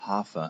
Ääntäminen
Ääntäminen GB : IPA : [əʊts] Haettu sana löytyi näillä lähdekielillä: englanti Käännös Ääninäyte Substantiivit 1.